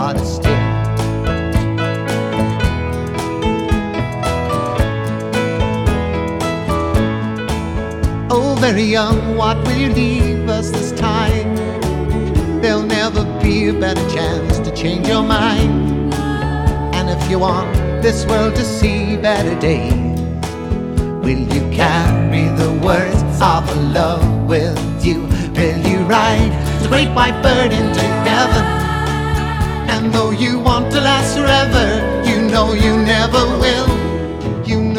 Soft Rock
Жанр: Поп музыка / Рок